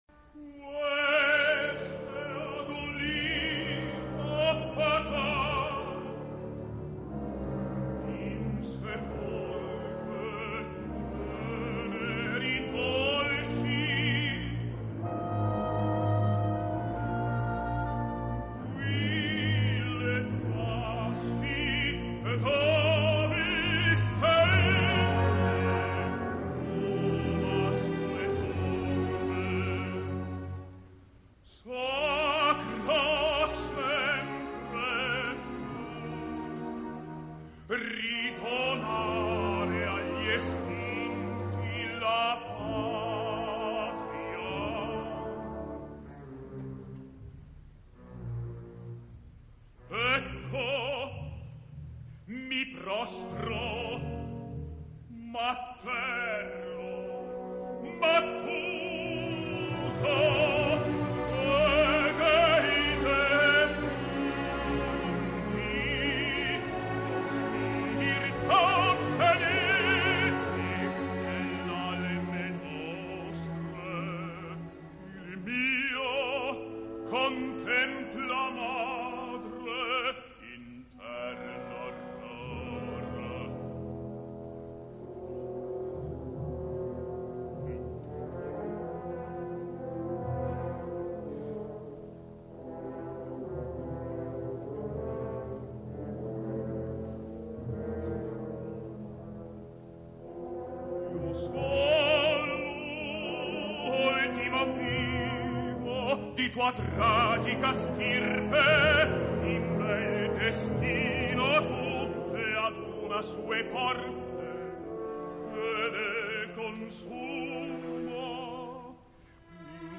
»melodramma / Melodram«
Dir.: G.Gavazzeni - Turin RAI
Nerone [Tenor]
Simon Mago [Bariton]